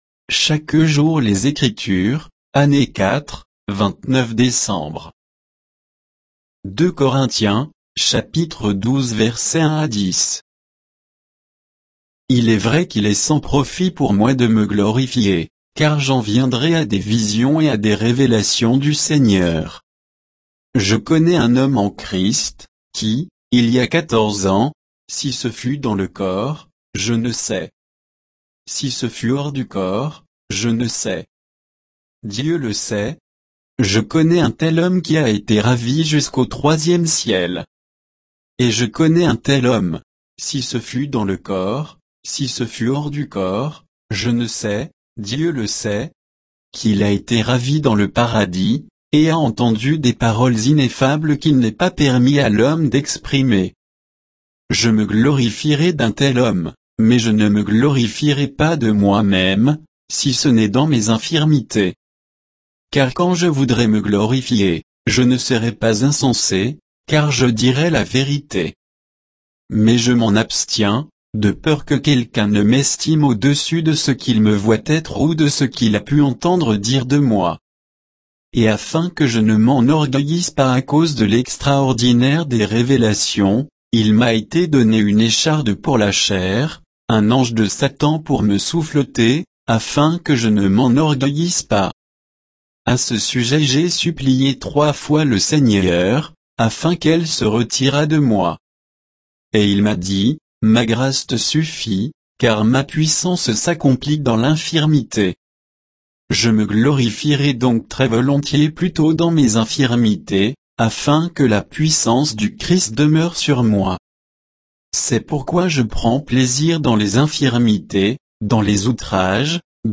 Méditation quoditienne de Chaque jour les Écritures sur 2 Corinthiens 12, 1 à 10